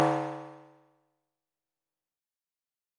Here’s an example of a 100x100 node simulation:
100x100 Grid Simulation